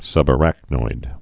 (sŭbə-răknoid)